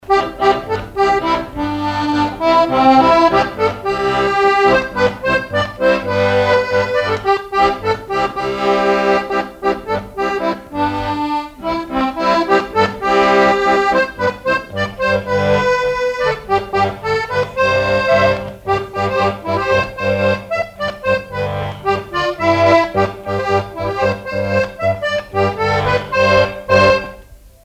instrumental
danse : quadrille : chaîne anglaise
accordéon chromatique
Pièce musicale inédite